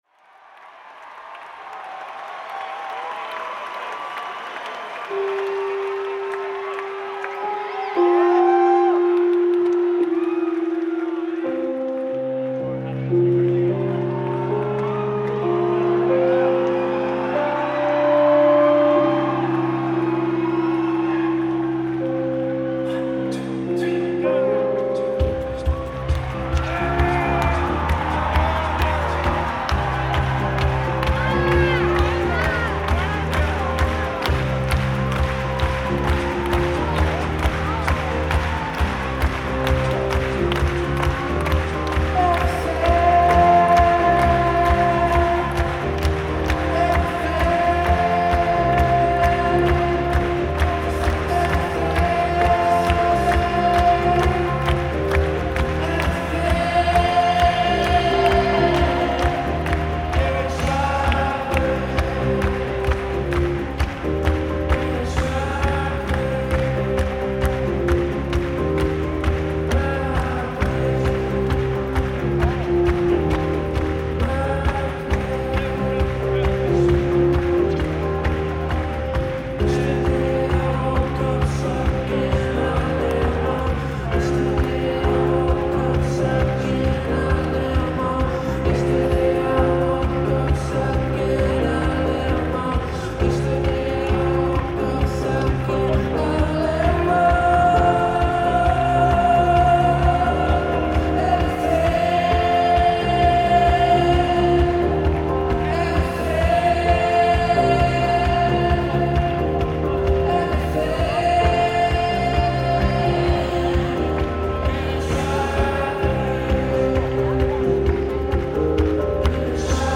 Live at the Bank of America Pavilion
encore 1…